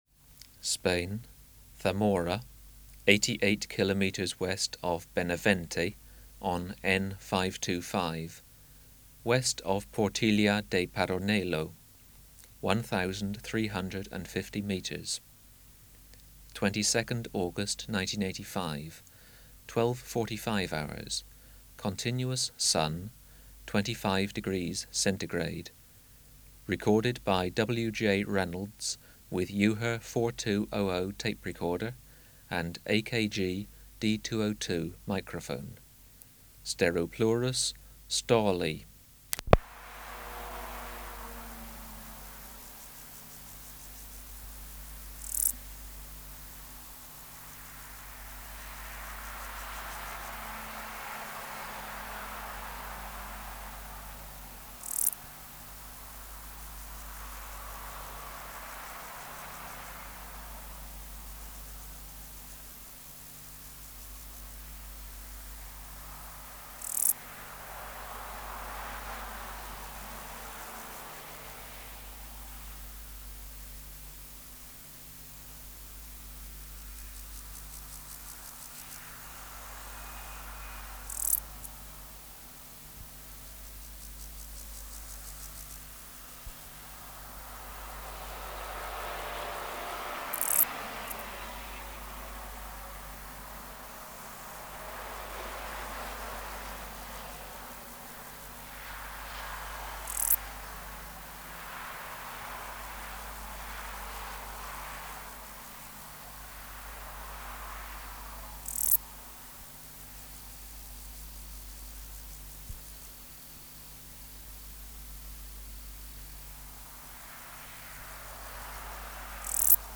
Natural History Museum Sound Archive Species: Steropleurus stali